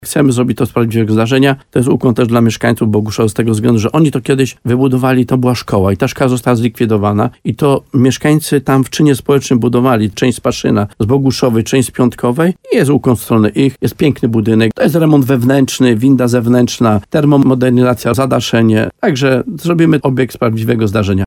– Na razie hula tam wiatr – mówi w programie Słowo za Słowo wójt gminy Chełmiec Stanisław Kuzak.